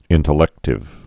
(ĭntl-ĕktĭv)